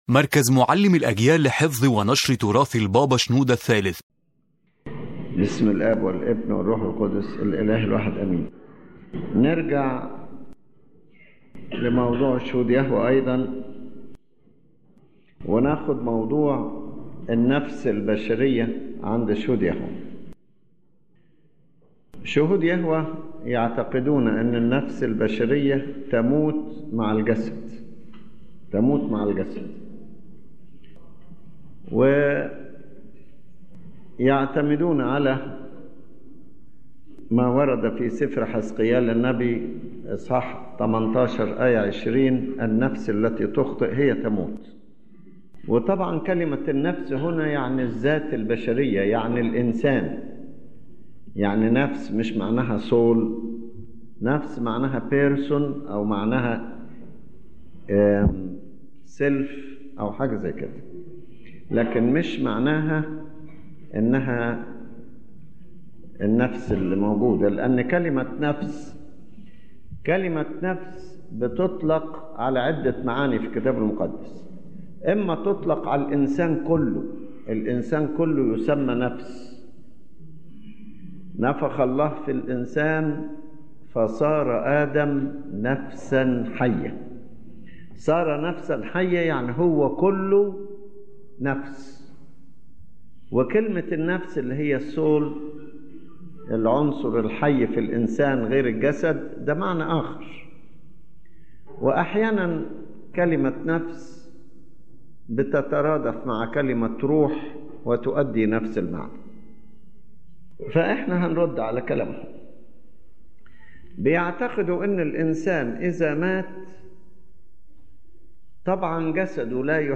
The lecture discusses the belief of Jehovah’s Witnesses that the human soul dies with the body, and presents the Orthodox response to this teaching, clarifying that the soul does not die but separates from the body and remains alive.